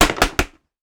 Foley Sports / Football - Rugby / Helmet Impact Clash.wav
Helmet Impact Clash.wav